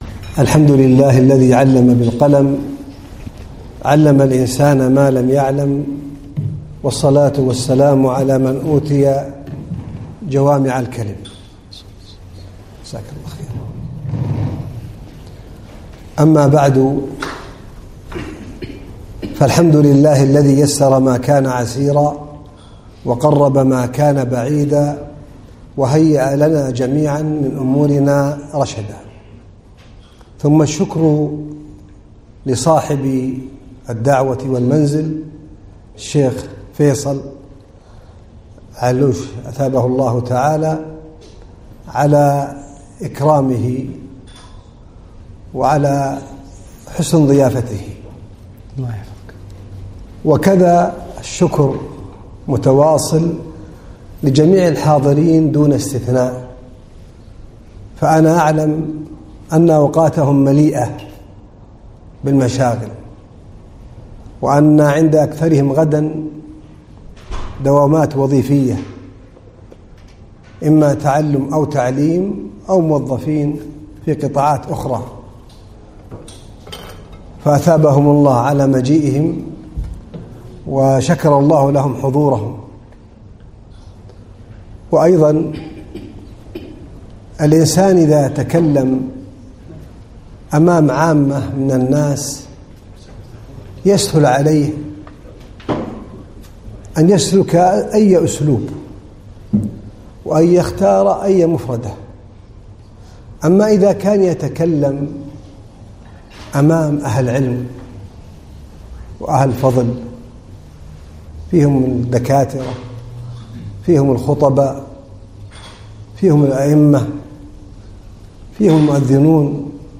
محاضرة - مواقف مع مشايخي دروس وعبر